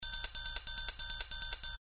忙碌的放克三角区
Tag: 140 bpm Funk Loops Drum Loops 300.09 KB wav Key : Unknown